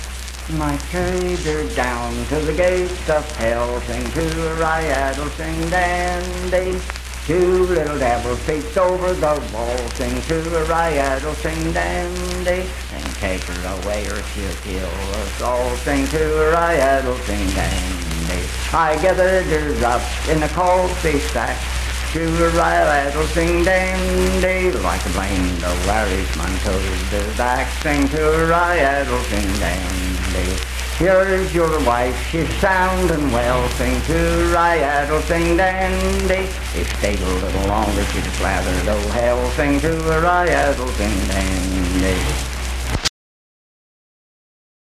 Unaccompanied vocal music performance
Verse-refrain 4 (4w/R).
Voice (sung)